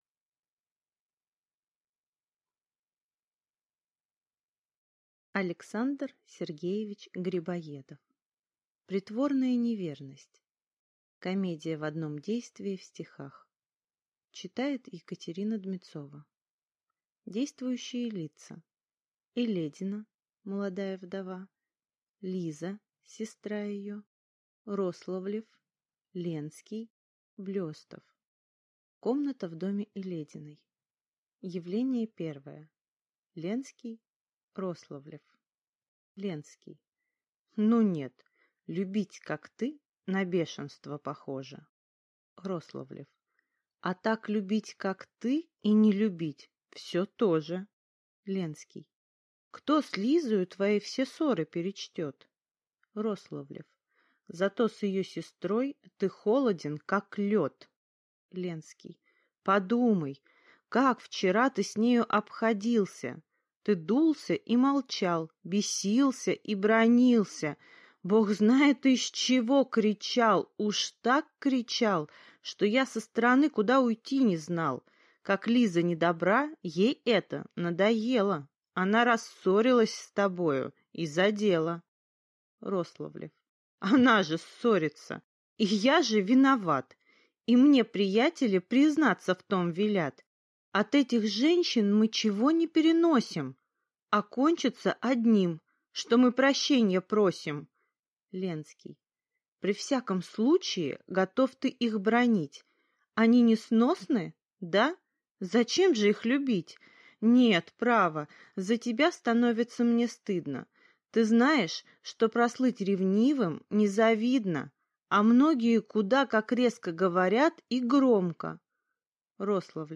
Aудиокнига Притворная неверность Автор Александр Грибоедов